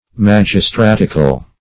Meaning of magistratical. magistratical synonyms, pronunciation, spelling and more from Free Dictionary.
Magistratical \Mag`is*trat"ic*al\